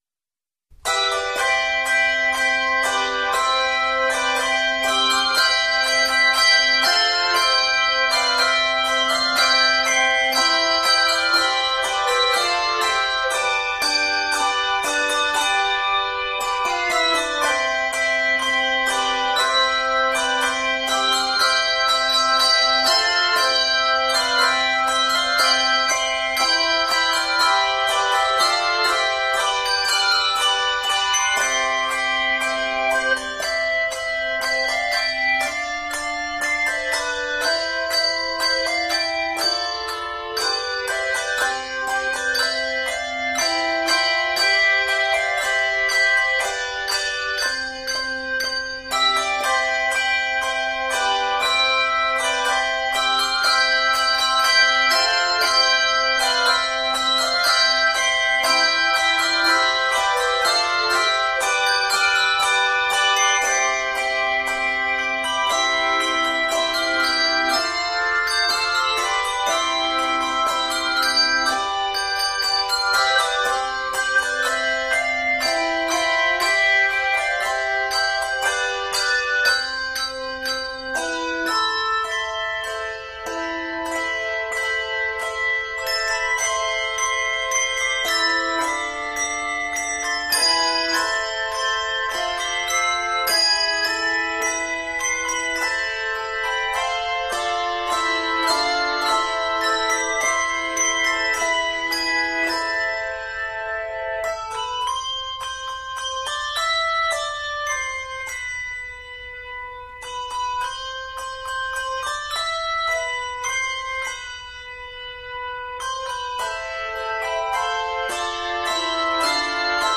scored in C Major